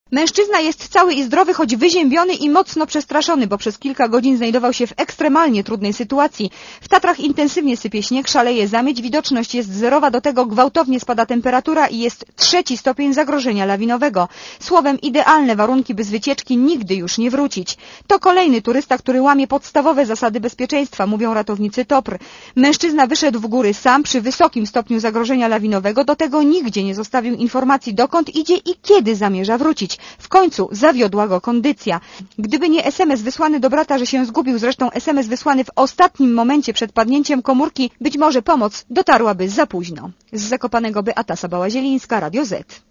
Źródło: Archiwum Relacja reportera Radia Zet Oceń jakość naszego artykułu: Twoja opinia pozwala nam tworzyć lepsze treści.